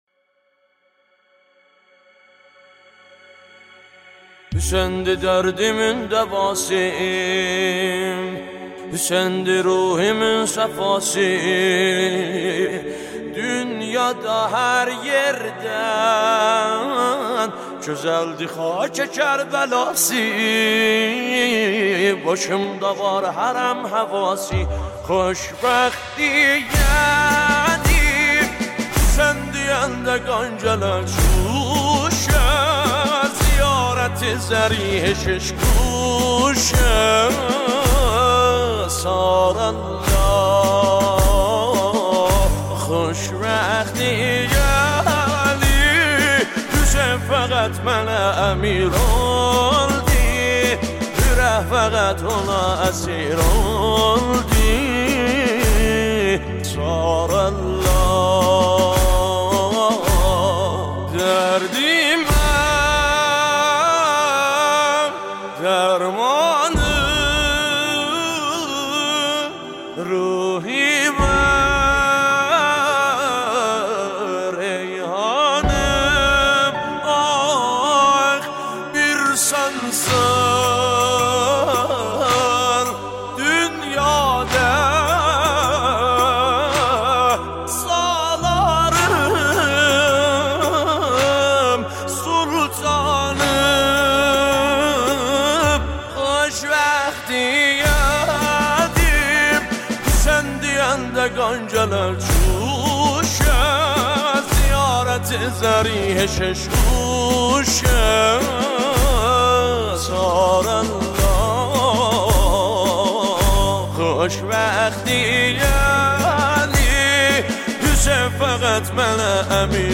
برچسب ها مداحی آذری نوحه ترکی حضرت امام حسین علیه السلام